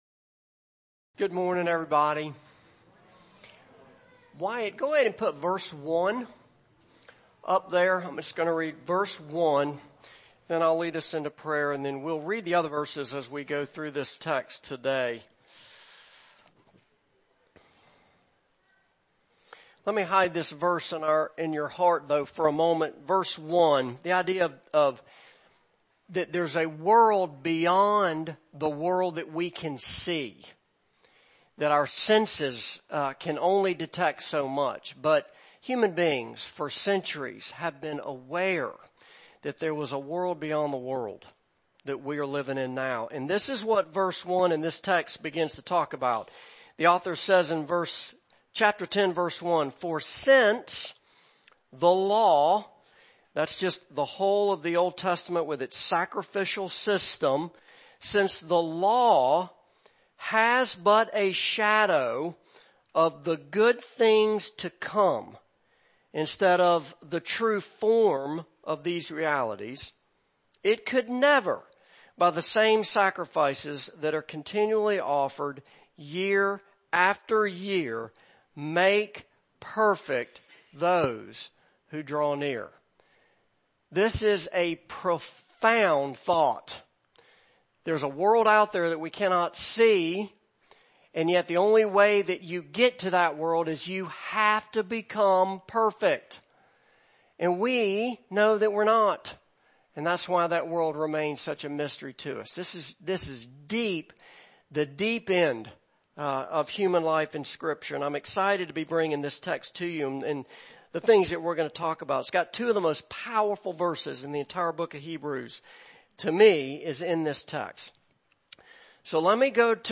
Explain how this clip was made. Passage: Hebrews 10:1-18 Service Type: Morning Service